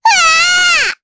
Estelle-Vox_Damage_jp_9.wav